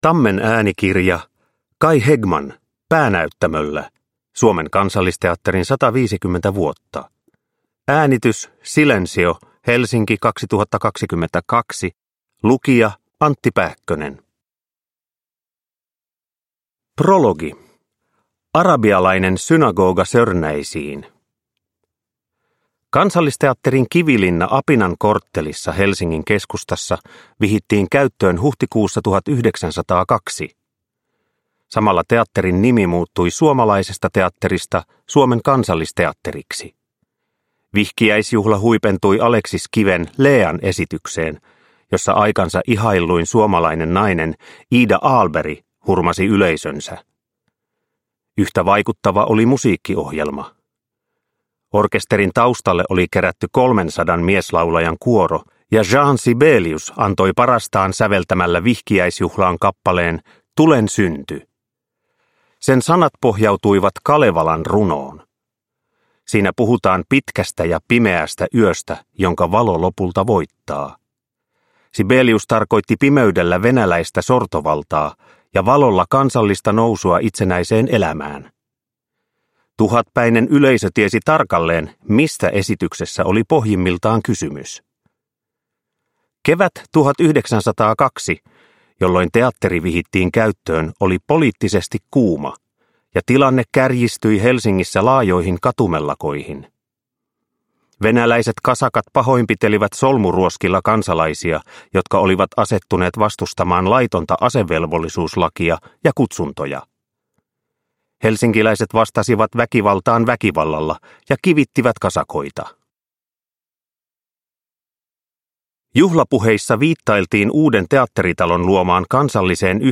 Päänäyttämöllä – Ljudbok – Laddas ner
Uppläsare: Antti Pääkkönen